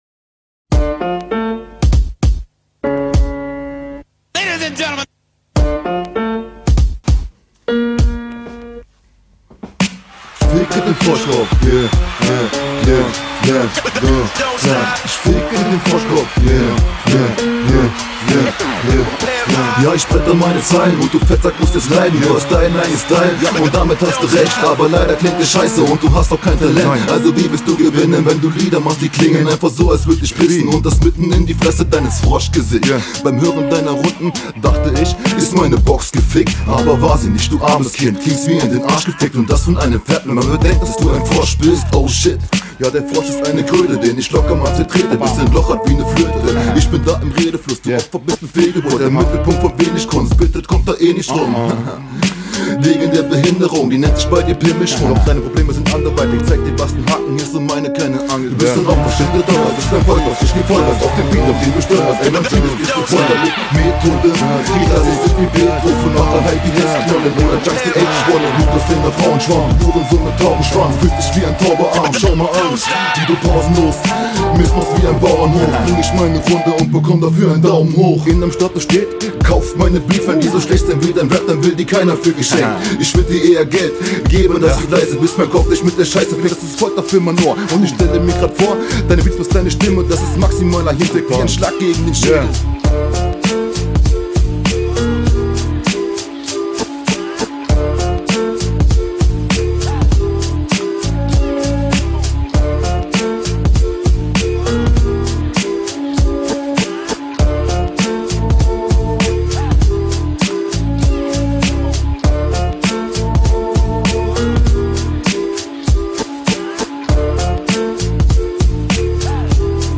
Flow: Wieder sehr geschmeidig. Bissl genuschelt diesmal.